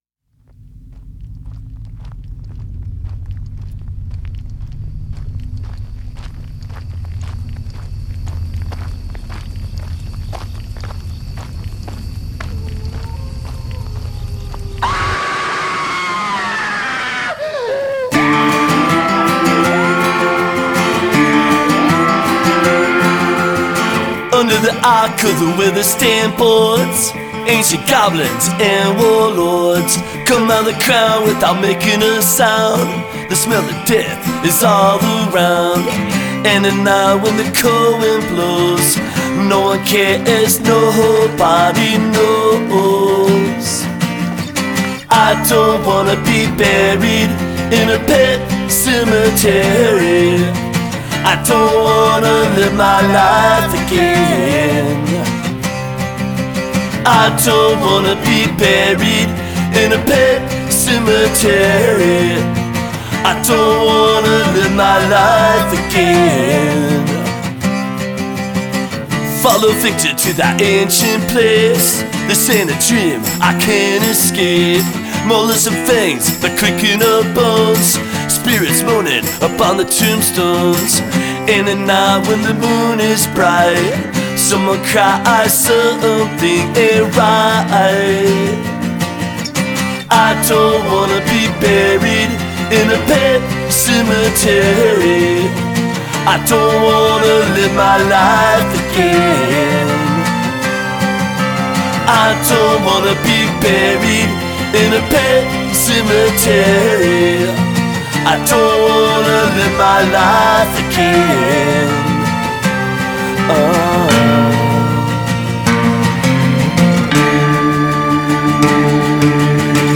Жанр: Punk Rock.